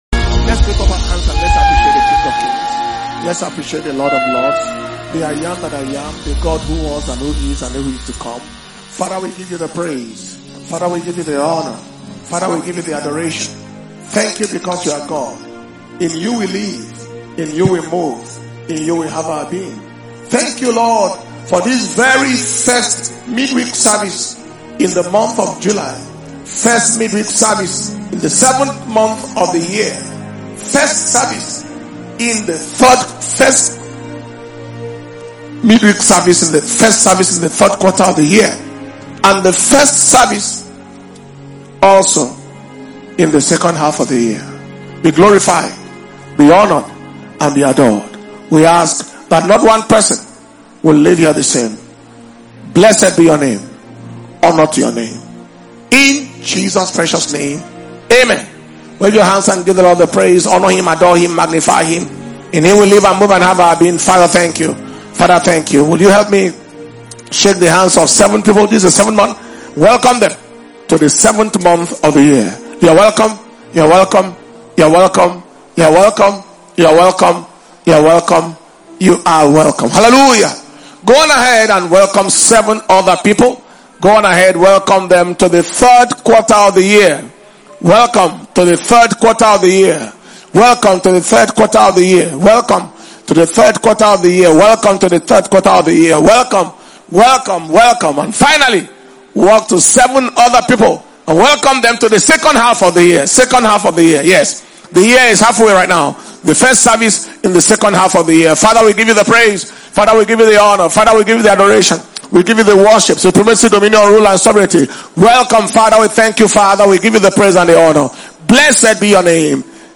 July 2025 Preservation And Power Communion Service